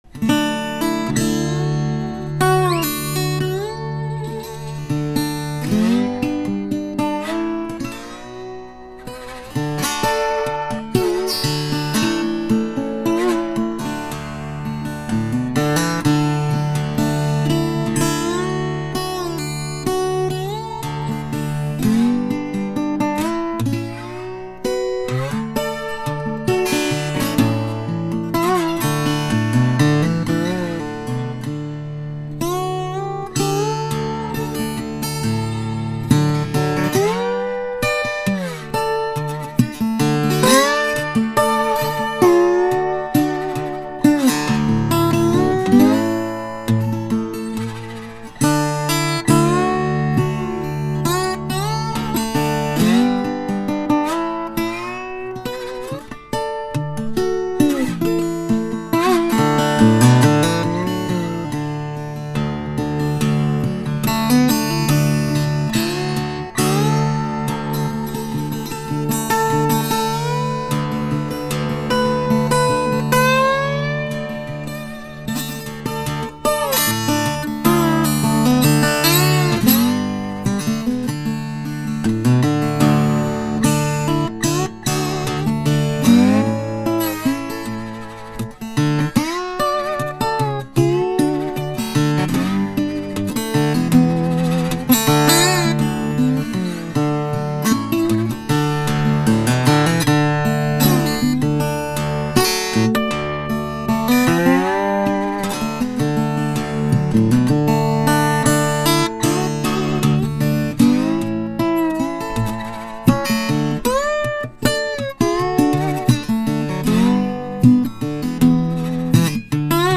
Slide Guitars
Recorded with RodeNT1a (a very flat condenser mike)
Blackwood top fixed bridge